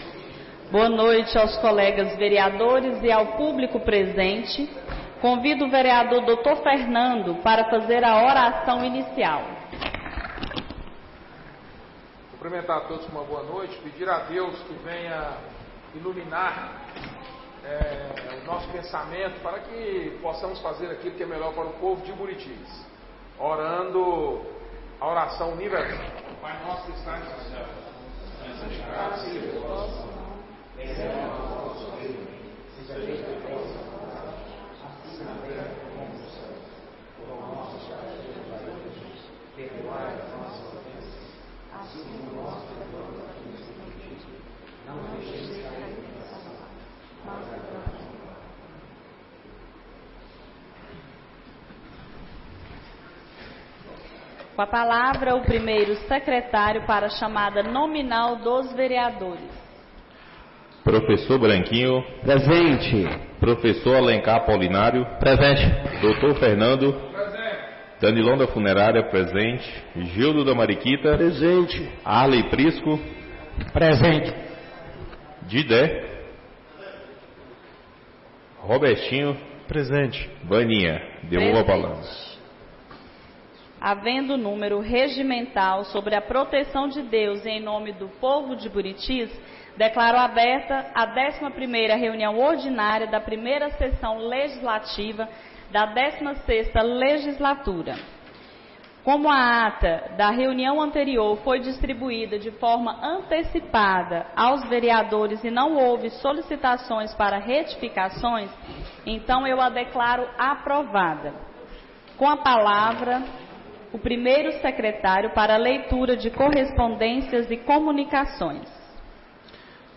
11ª Reunião Ordinária da 1ª Sessão Legislativa da 16ª Legislatura - 31-03-25